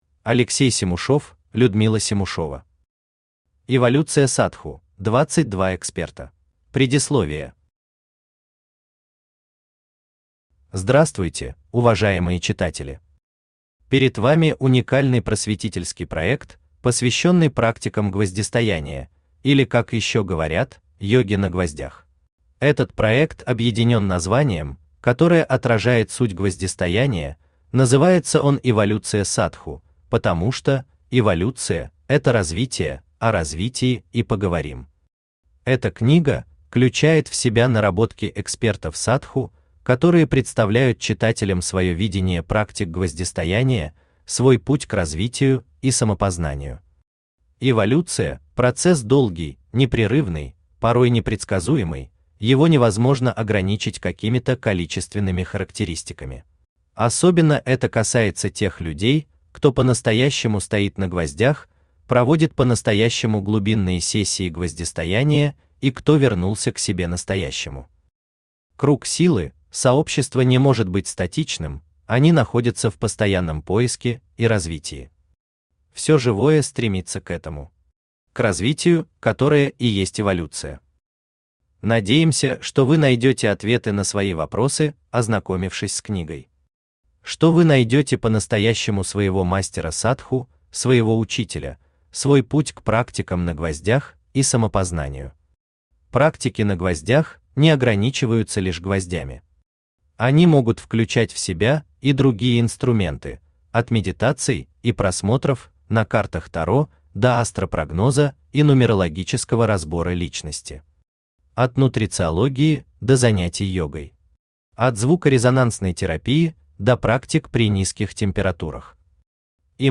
Аудиокнига Эволюция Садху: 22 Эксперта | Библиотека аудиокниг